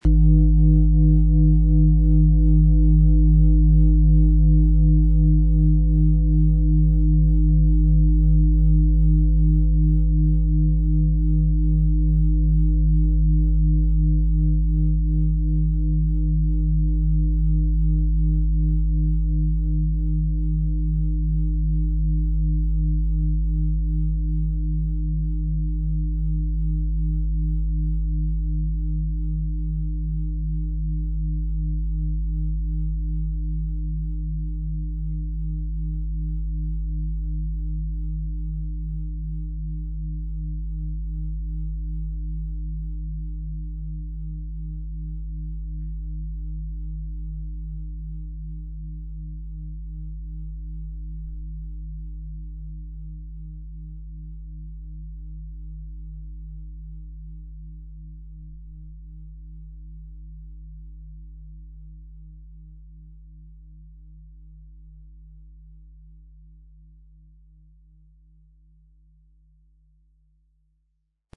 Der Klang der universellen Harmonie.
Wie klingt diese tibetische Klangschale mit dem Planetenton Wasserstoffgamma?
Der Schlegel lässt die Schale harmonisch und angenehm tönen.